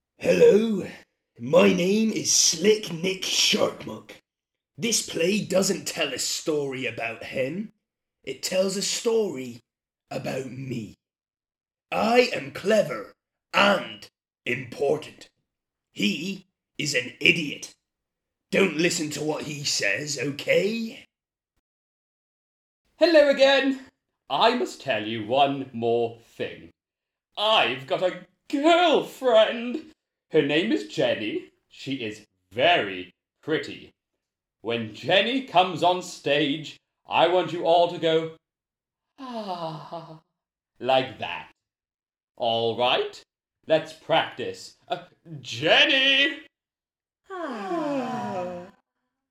Erlebt ein spannendes Piratenabenteuer voller Mut, Freundschaft und verrückter Wendungen – gespielt in einfachem Englisch!